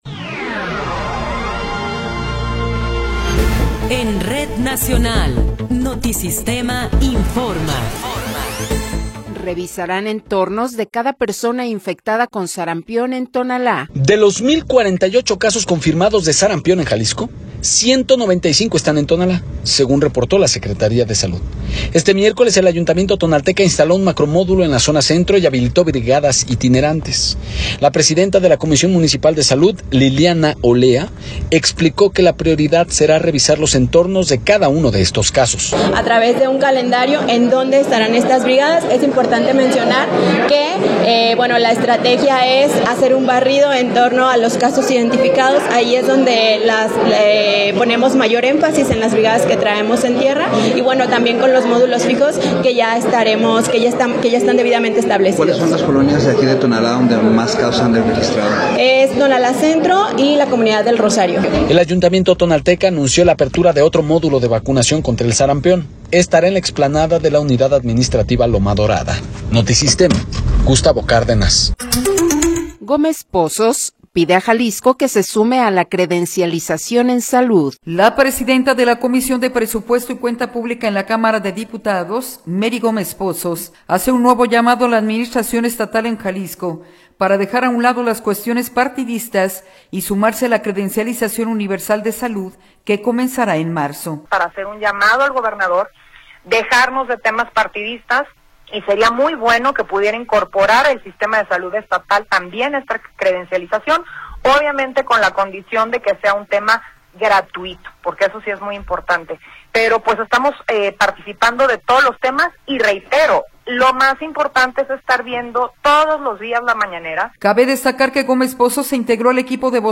Noticiero 15 hrs. – 21 de Enero de 2026